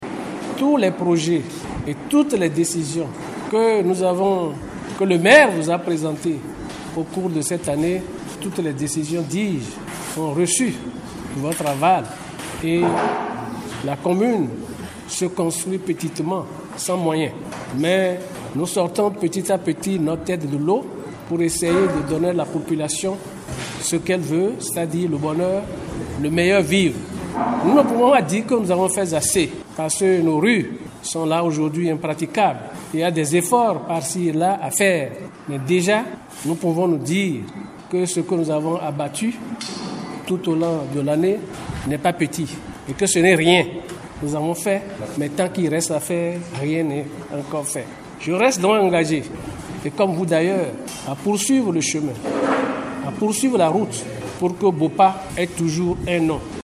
A l’ouverture des travaux de cette session, l’édile de Bopa a salué la clairvoyance de l’ensemble des membres du conseil communal qui donnent à la mairie les moyens de sa politique. Tout en leur remerciant pour leur soutien indéfectible, le maire Abel Djossou a exhorté ses pairs à plus de sacrifice pour l’atteinte des objectifs, gage du développement de la Cité du parc des bœufs.